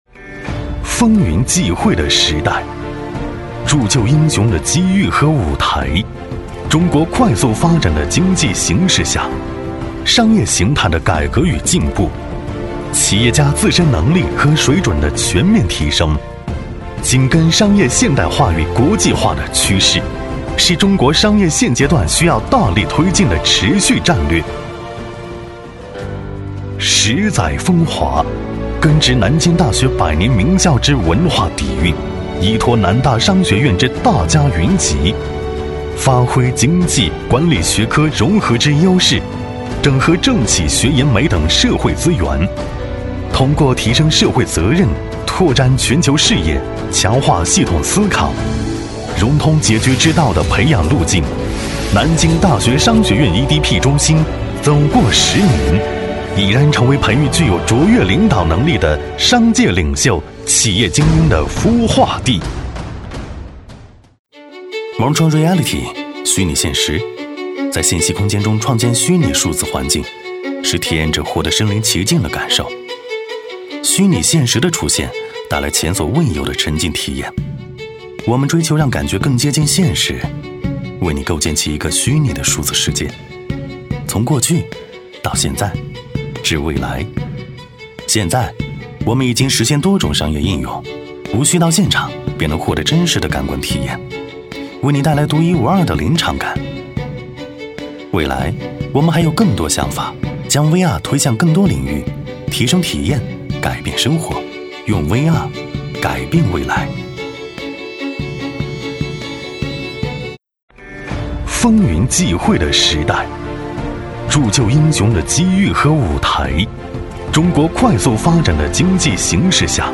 男S356 国语 男声 广告-VR-随性时尚 激情激昂|大气浑厚磁性|科技感|积极向上